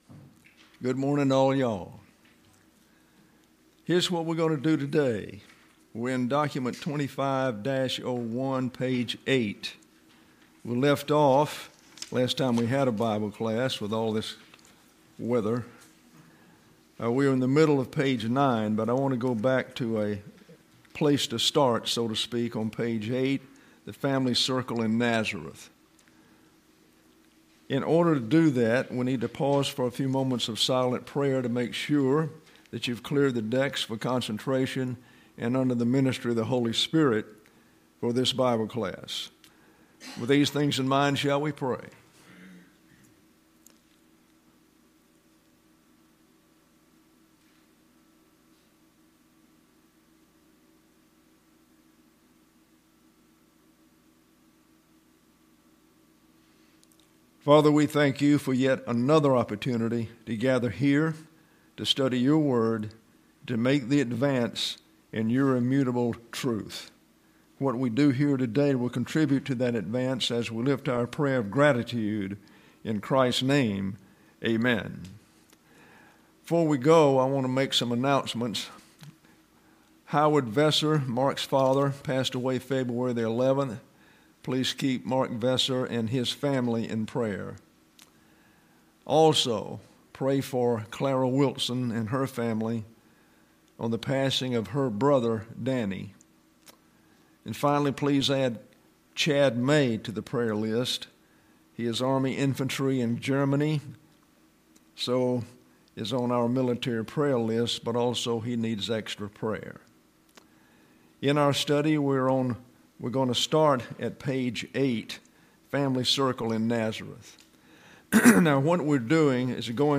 The Letter of James: Summary: Lesson 2: Commentaries on the Letter of James: A. T. Robertson; Douglas J. Moo;